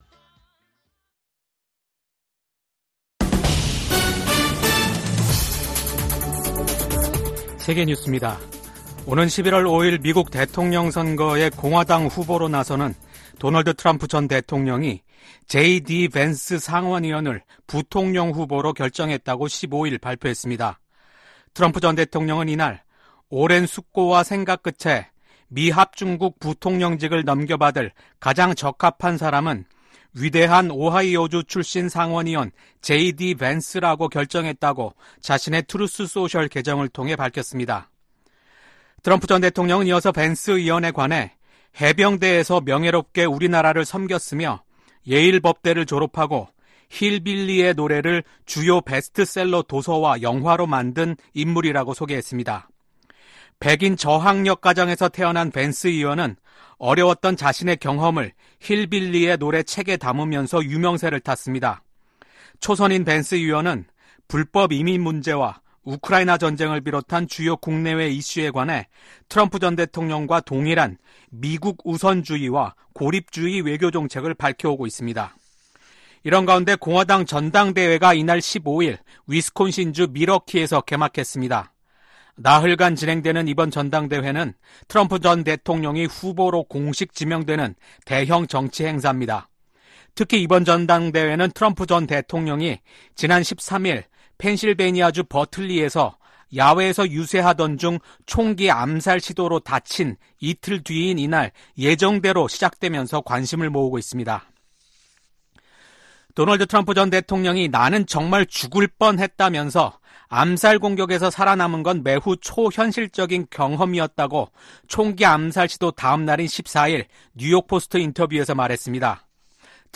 VOA 한국어 아침 뉴스 프로그램 '워싱턴 뉴스 광장' 2024년 7월 16일 방송입니다. 조 바이든 미국 대통령은 트럼프 전 대통령 피격 사건이 나자 대국민 연설을 통해 폭력은 결코 해답이 될 수 없다고 강조했습니다. 도널드 트럼프 전 대통령에 대한 총격 사건과 관련해 미국 정치권과 각국 정상은 잇달아 성명을 내고 트럼프 전 대통령의 빠른 쾌유를 기원했습니다.